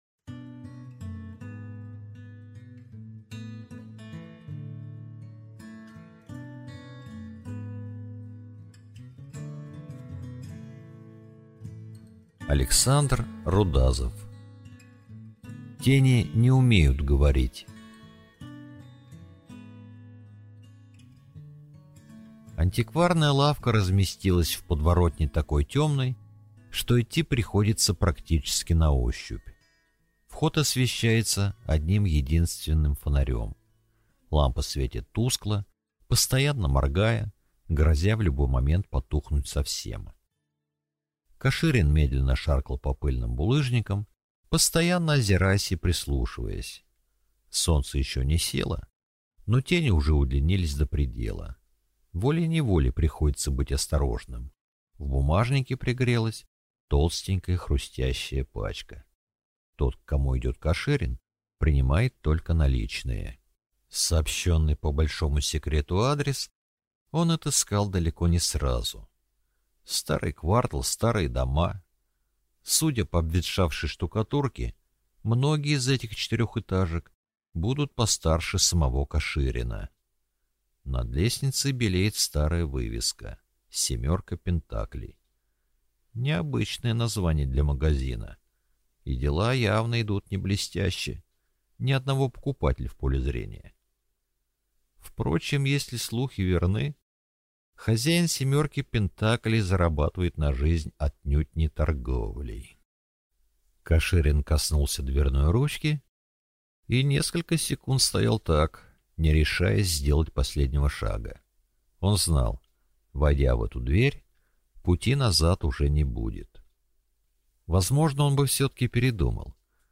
Аудиокнига Тени не умеют говорить | Библиотека аудиокниг